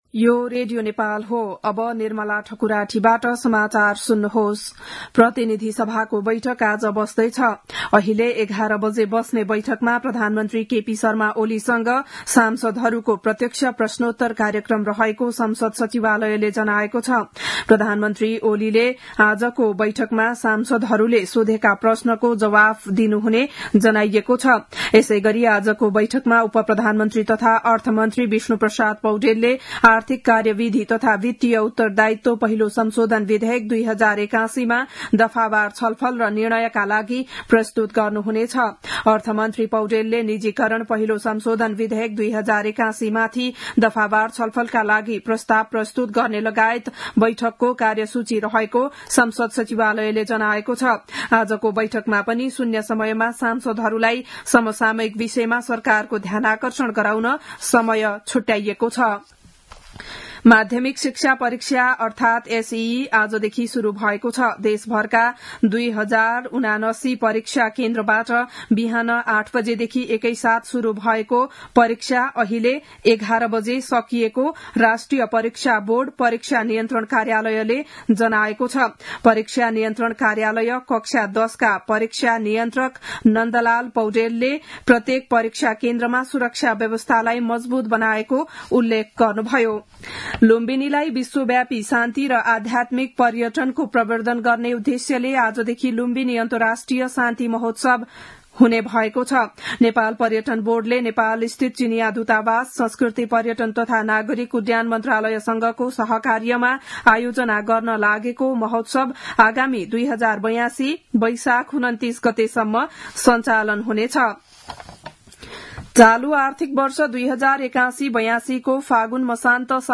बिहान ११ बजेको नेपाली समाचार : ७ चैत , २०८१